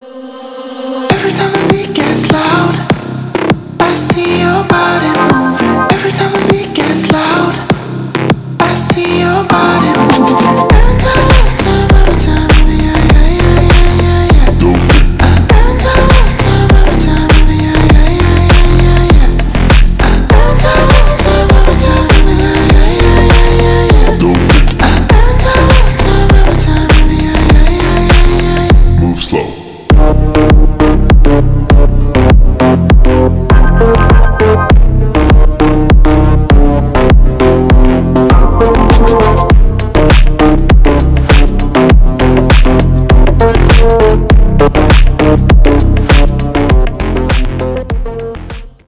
мощные басы
Midtempo
Bass House
клубная музыка
танцевальная мелодия